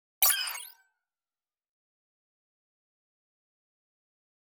دانلود آهنگ اعلان خطر 4 از افکت صوتی اشیاء
جلوه های صوتی
دانلود صدای اعلان خطر 4 از ساعد نیوز با لینک مستقیم و کیفیت بالا